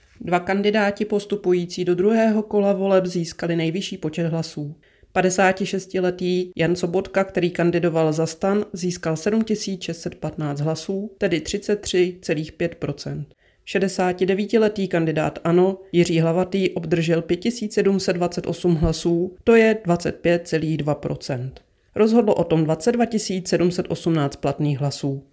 Vyjádření místopředsedkyně ČSÚ Evy Krumpové, soubor ve formátu MP3, 991.88 kB